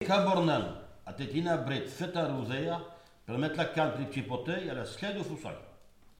Enquête Arexcpo en Vendée
Catégorie Locution